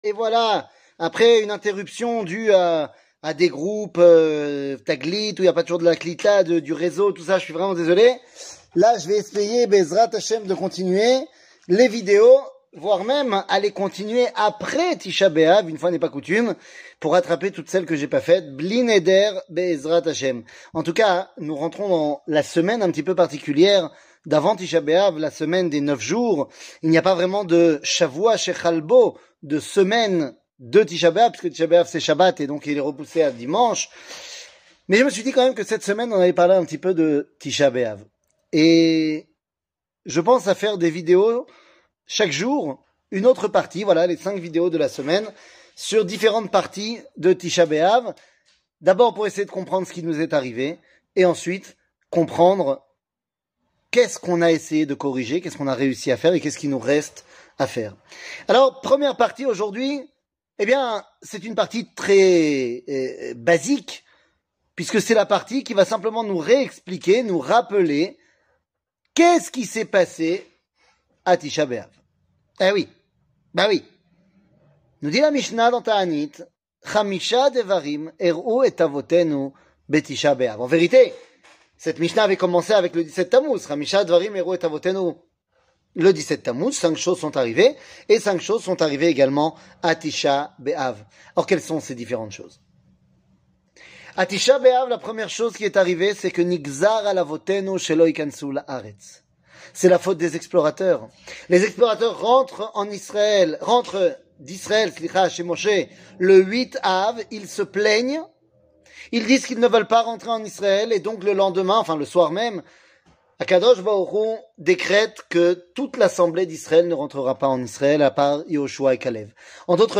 קטגוריה 9 Av partie 1 00:06:48 9 Av partie 1 שיעור מ 31 יולי 2022 06MIN הורדה בקובץ אודיו MP3 (6.23 Mo) הורדה בקובץ וידאו MP4 (14.41 Mo) TAGS : שיעורים קצרים ראה גם רוצים לתרום לעמותה של הרב ?